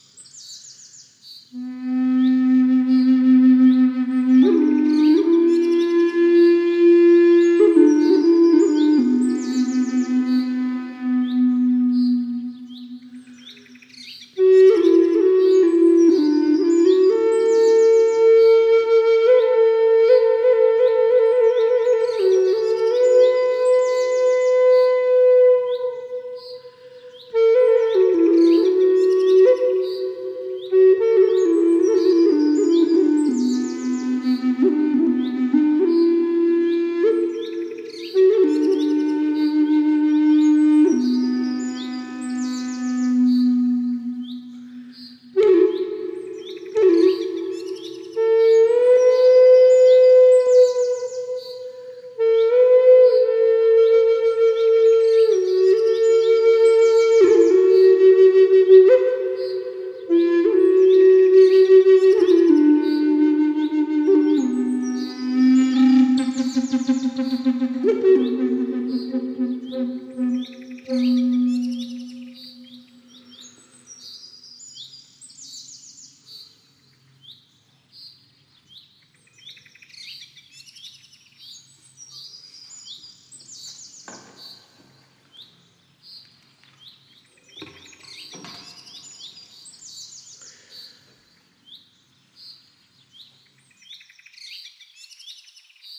Super Bass Lakota flute in key of C (Do) – Avaye Lotus
Walnut wood, approximately 70cm long, with a stable coating and resistant to environmental factors, along with a bag, birth certificate, one-year warranty and free shipping.
lakota-key-do-super-BASS.mp3